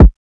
kick 16.wav